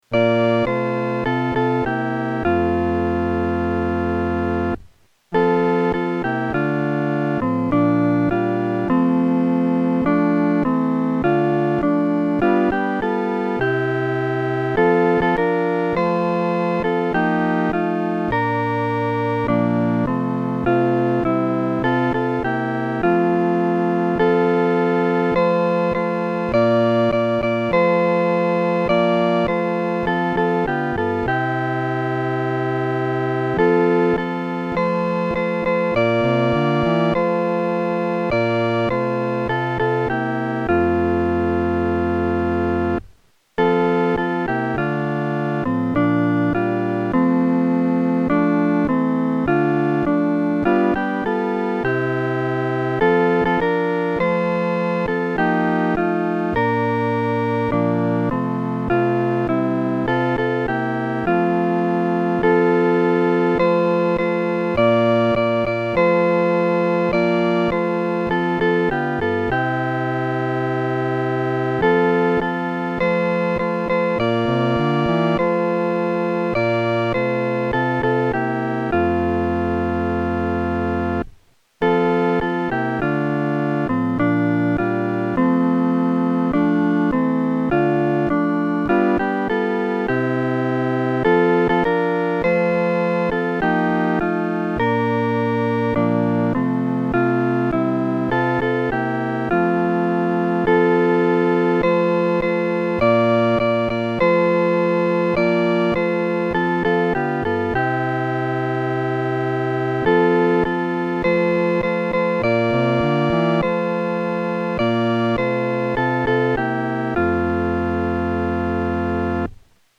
伴奏
四声
这首诗的曲调富有感恩的情绪，心中的欢乐不彰表于外，而在其中自然流露。